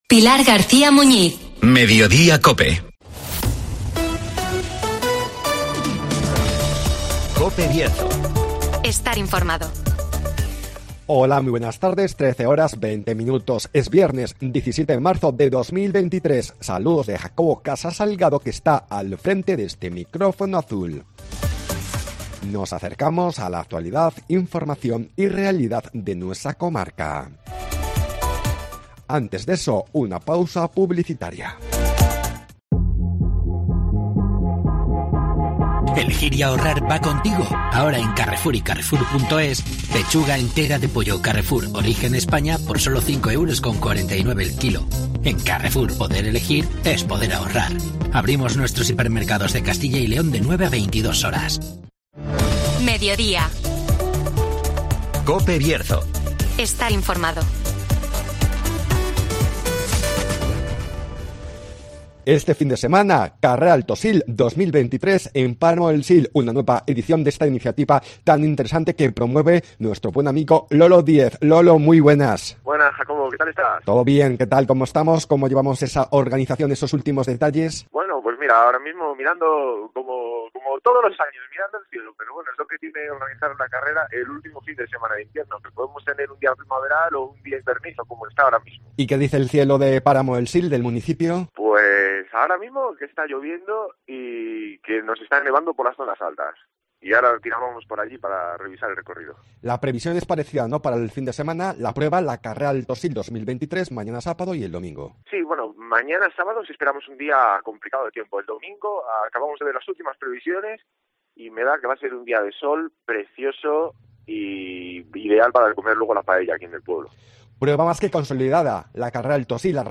Este fin de semana, carrera Alto Sil 2023 en Páramo del Sil (Entrevista